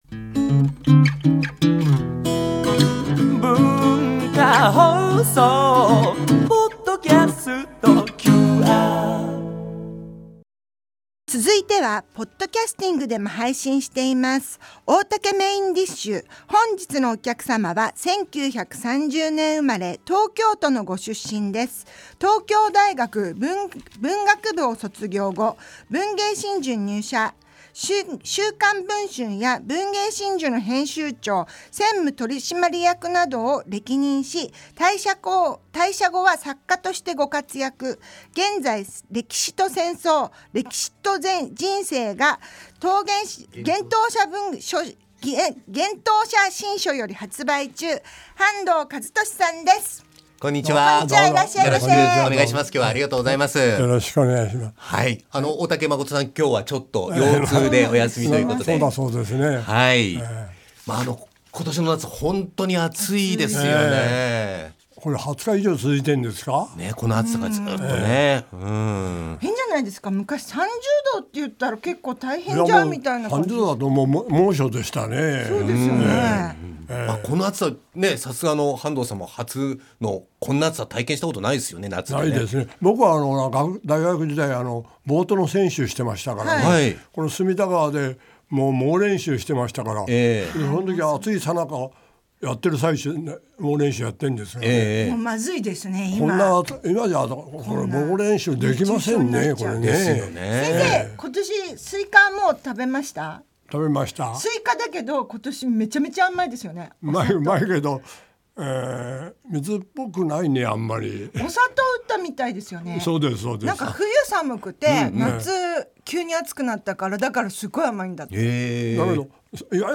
8月3日：半藤一利 番組のメインを飾るゲストが登場！ 大竹まこと＆各パートナーがお客様のトークを料理します。